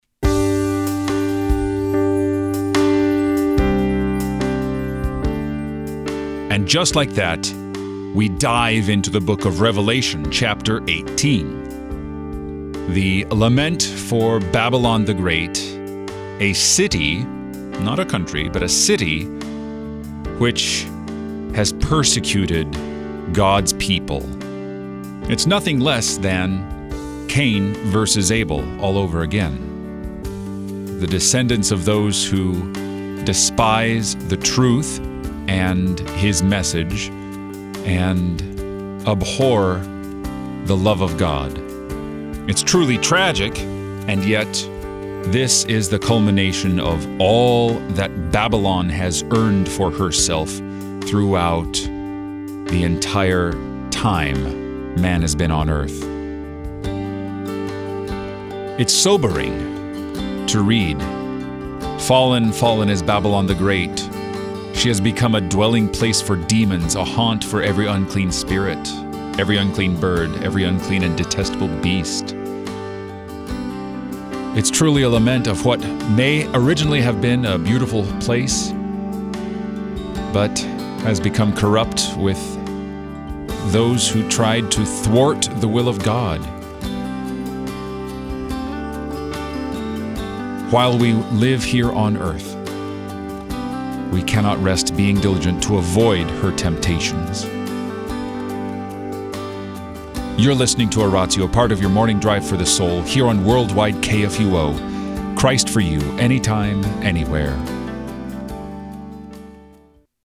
and also gives a short meditation on the day’s scripture lessons.